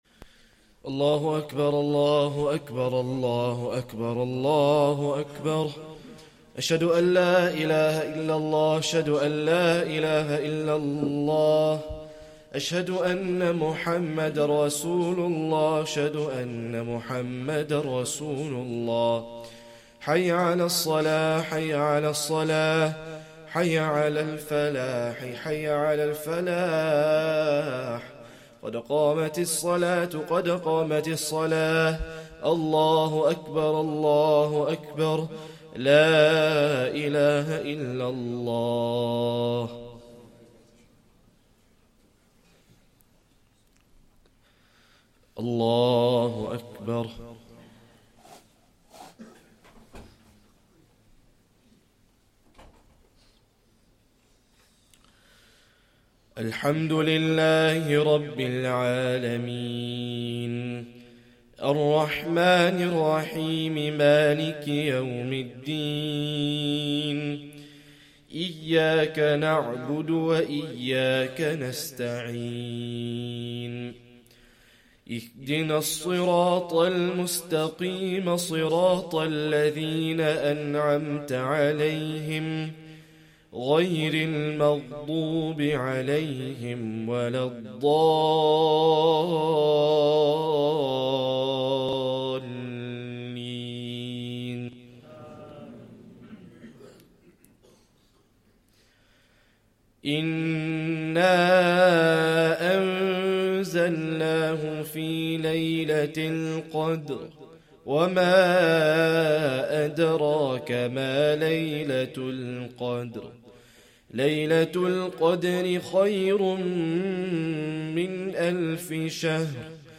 Esha + 1st Tarawih prayer - 17th Ramadan 2024